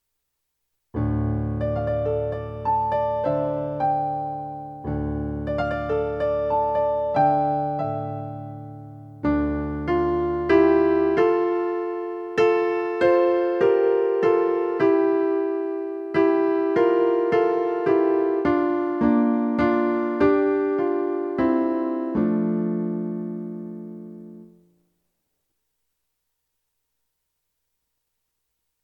Create In Me - Refrain - Soprano/Alto